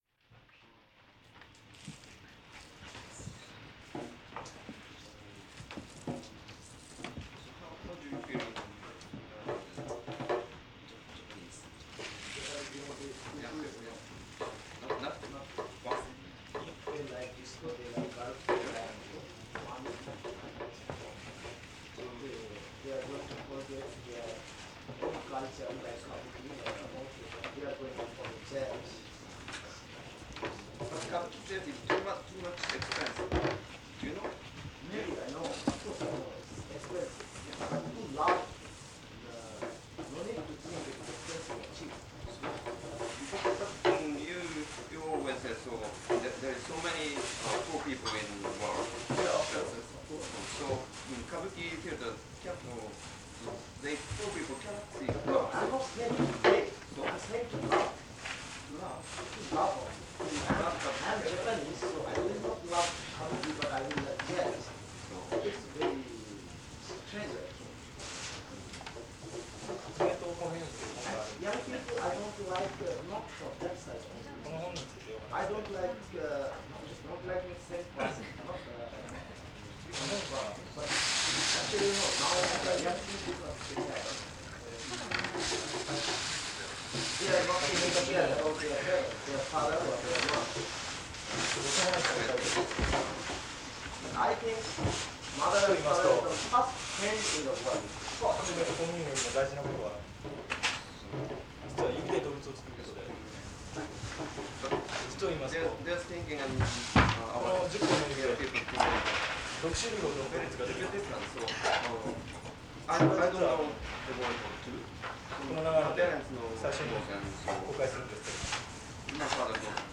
22_live_at_Buddha,_Nagano_(11)_the_glasses_school.mp3